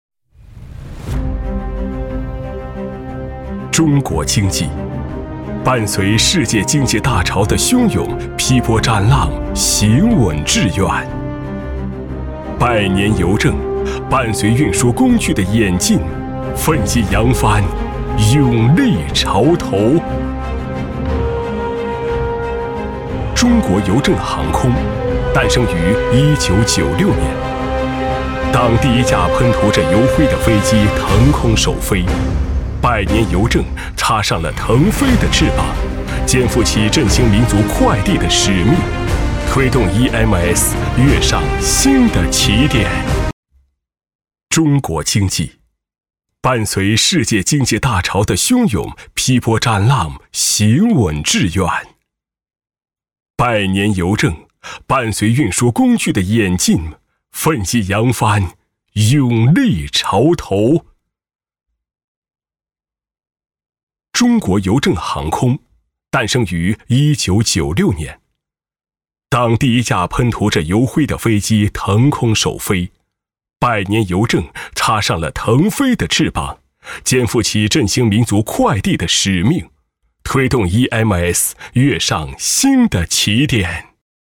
男210-【大气专题】中国邮政航空公司
男210浑厚专题 210
男210--大气专题-中国邮政航空公司.mp3